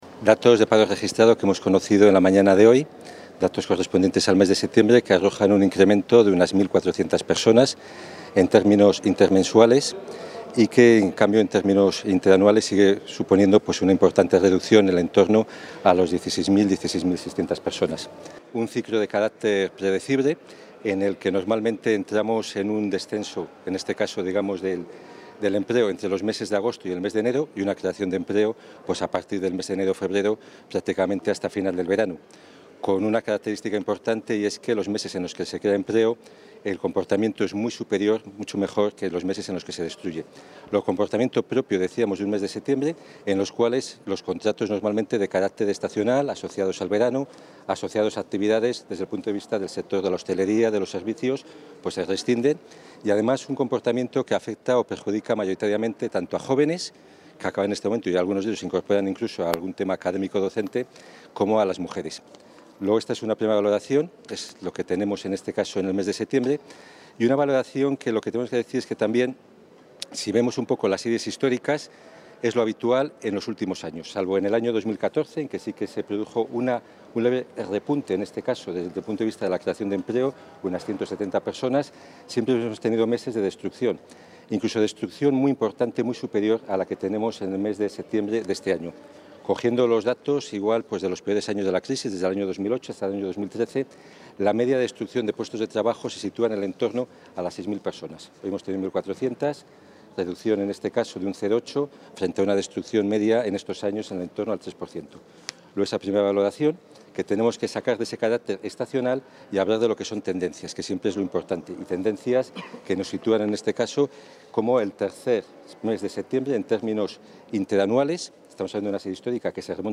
Valoración de los datos del paro del mes de septiembre Contactar Escuchar 4 de octubre de 2016 Castilla y León | El viceconsejero de Empleo y Diálogo Social, Mariano Gredilla, ha valorado hoy los datos del paro del mes de septiembre.